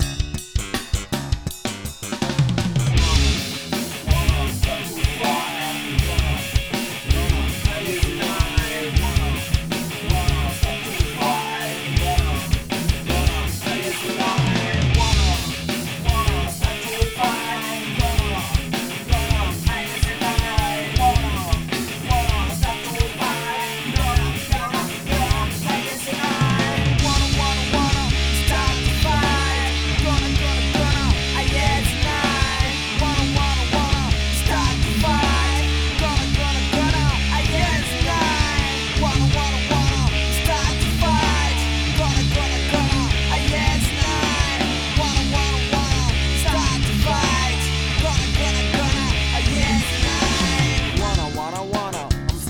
Voilà comment ça sonne un 2203 VHM :
Sur la première partie il y a un 2203 VHM seul sur la seconde les pistes sont doublées avec un autre ampli.
Celui que j'ai ne souffle pas et est certainement un des 2 ou 3 meilleurs amplis que je possède.